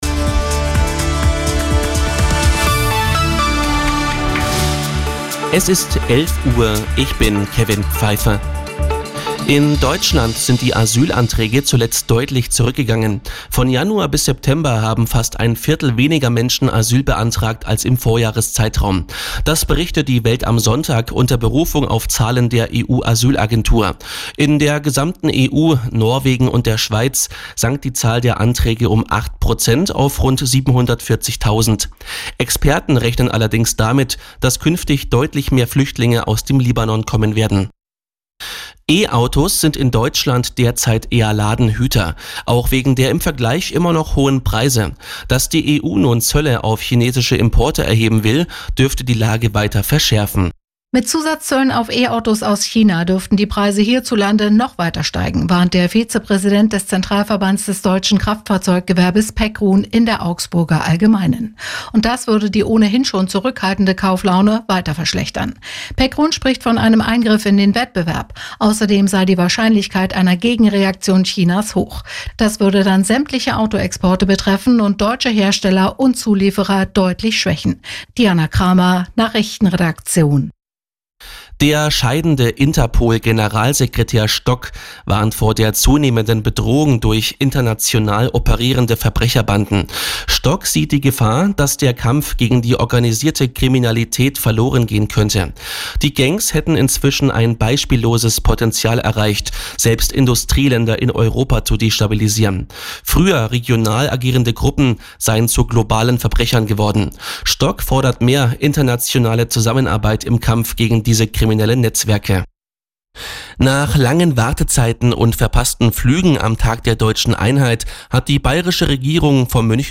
Die Radio Arabella Nachrichten von 14 Uhr - 05.10.2024